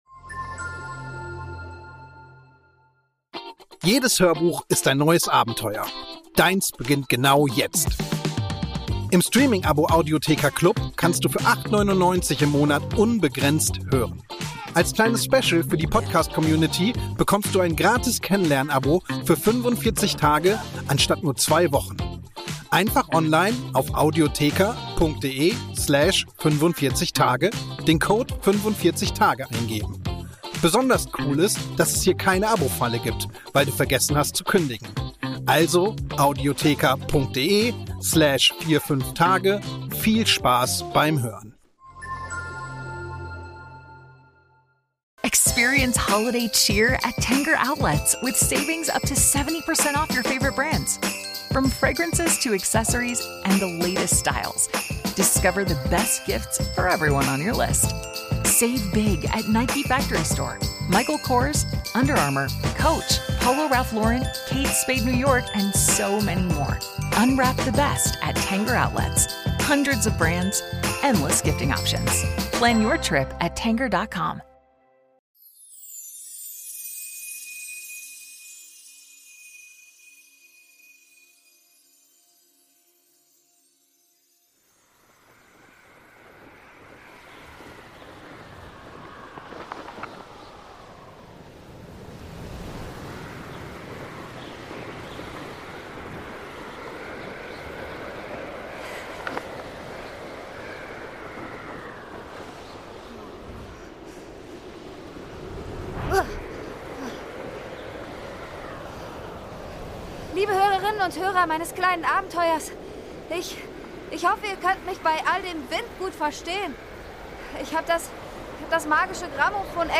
7. Türchen | Quidditchstar Betty O’Hare - Eberkopf Adventskalender ~ Geschichten aus dem Eberkopf - Ein Harry Potter Hörspiel-Podcast Podcast